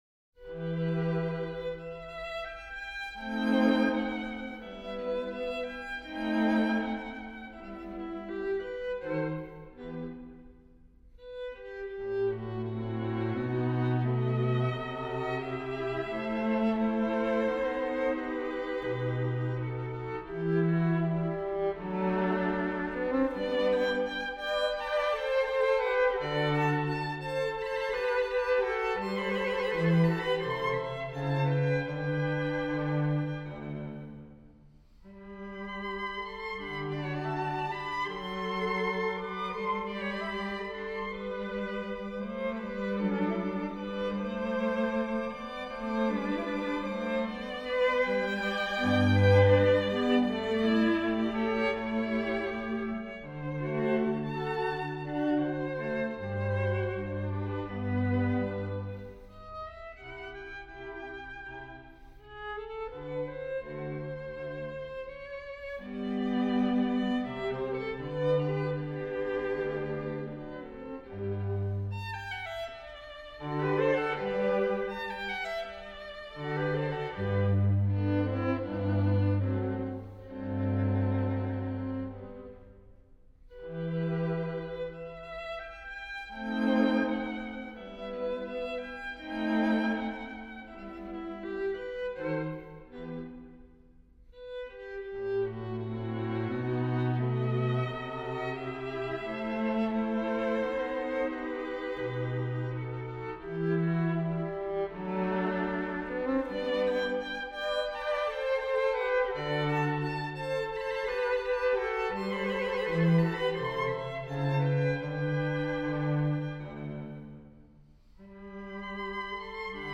Bach, Himnos para Adviento y Navidad, BWV 722 (Choralvorspiel) BWV 314 (Choralsatz) Gelobet seist du, Jesu Christ (Bendito seas, Jesucristo)